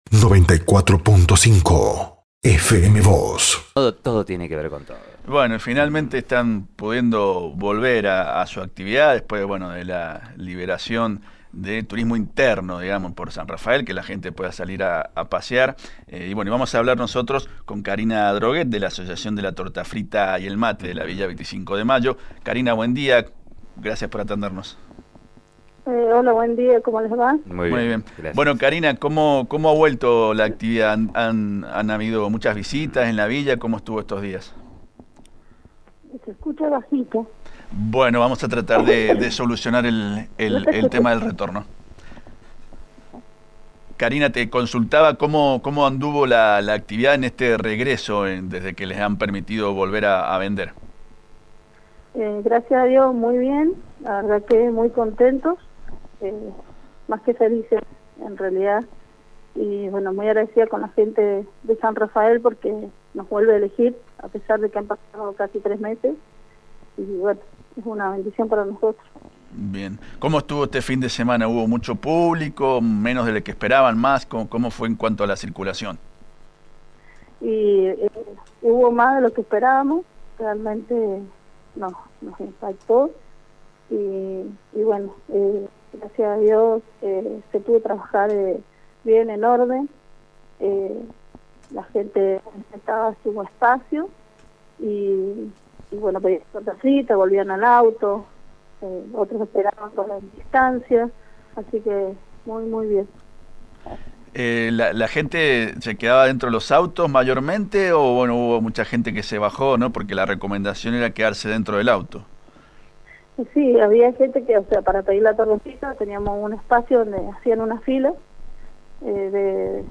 en diálogo con FM Vos (94.5) y Diario San Rafael